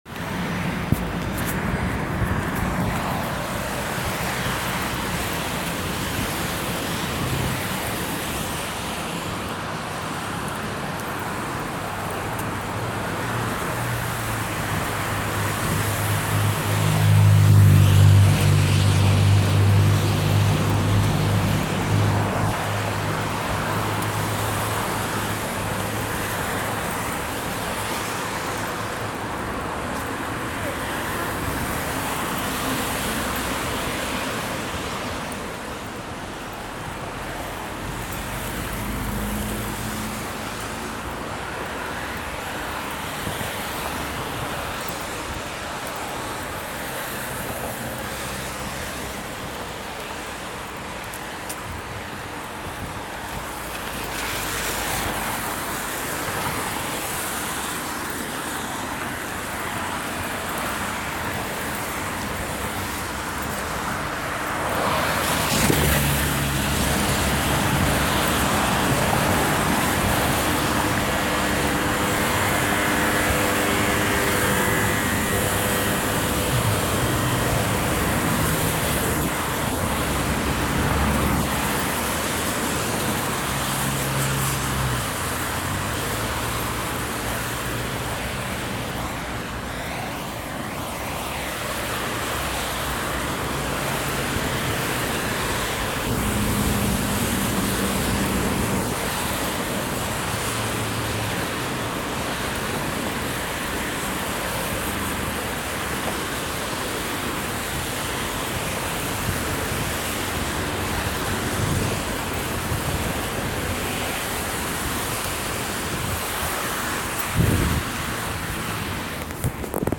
Street sound
Walking home in Kyiv, Ukraine